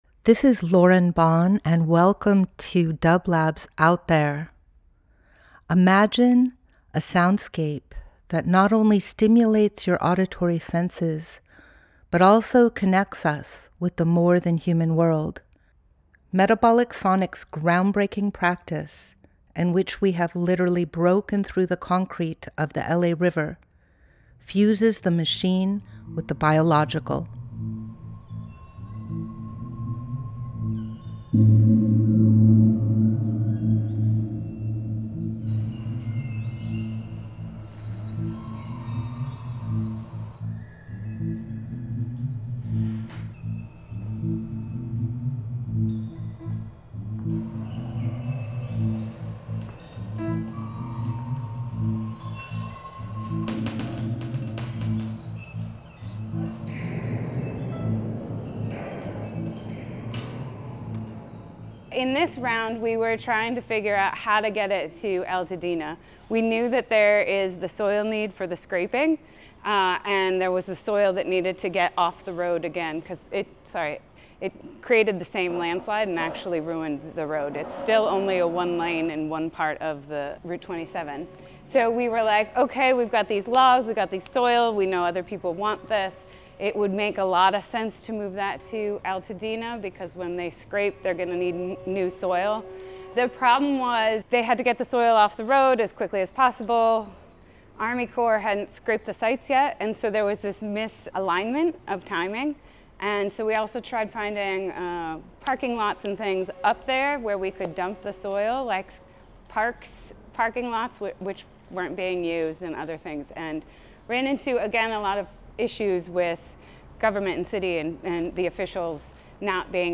Each week we present a long-form field recording that will transport you through the power of sound.
The Moon Tour, Part One – This is an introduction to, and a community discussion about Metabolic Studio’s project Moving Mountains, which redistributes healthy topsoil from landslides in the art form Meandros. This discussion took place on June 27, 2025 at a tour of “The Moon”, a property that has been un-developed and is supporting native plant re-growth. The discussion is interspersed with improvised music by Metabolic Sonics.